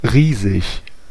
Ääntäminen
IPA: /ˈʀiːzɪç/